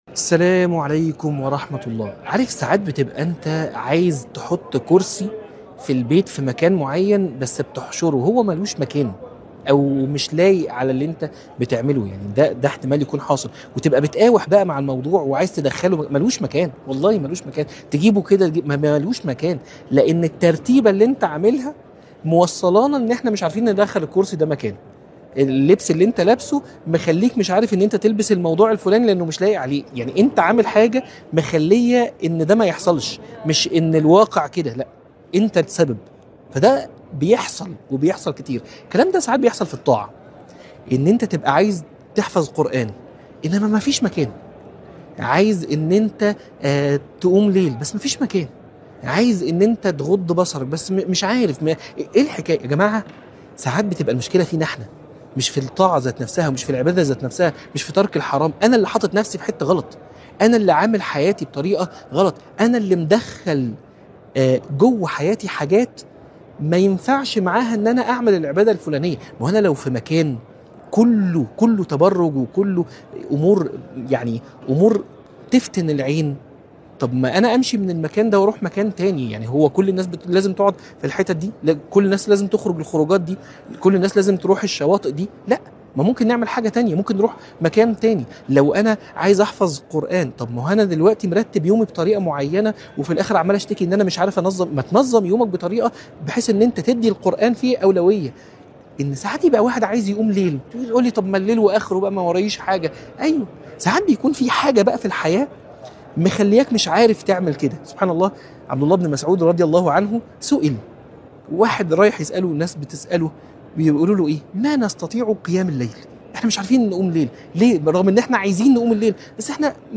عنوان المادة سبب مشكلتك إن مفيش مكان - من الحرم